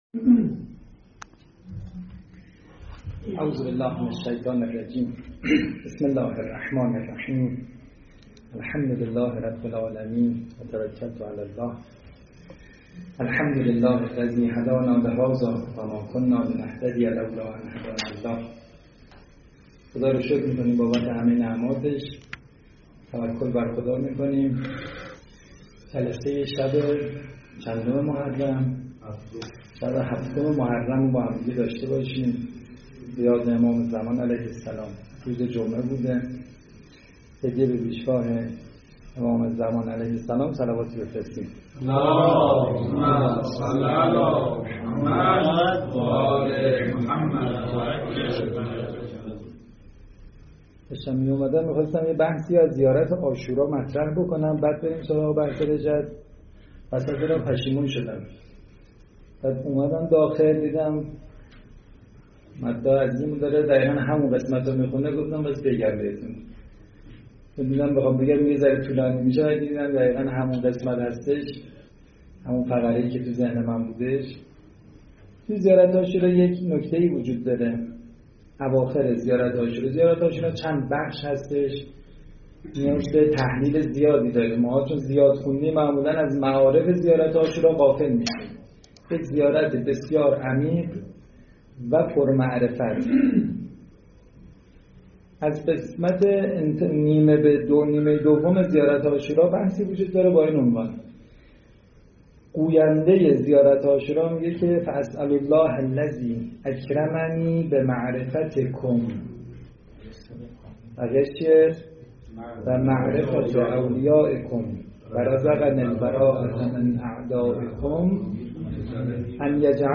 سخنرانی های